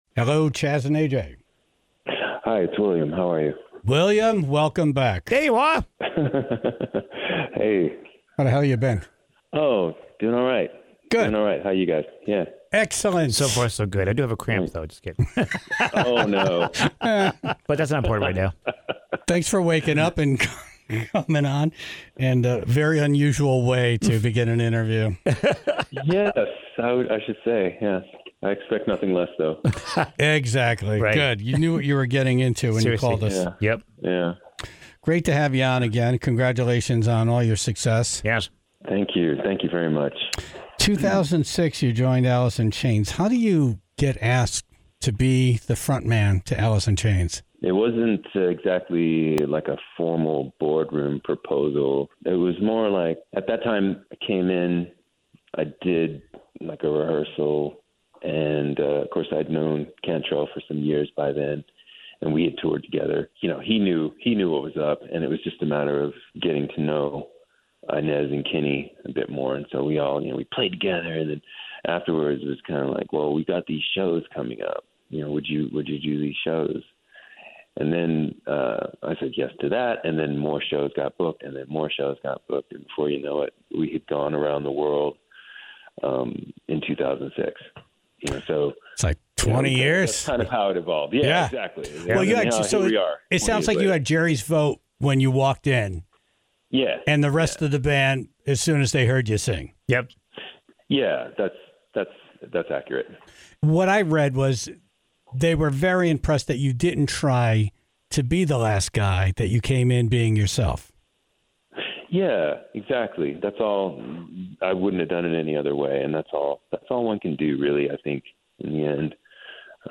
Pod Pick: An Interview With William DuVall Of Alice In Chains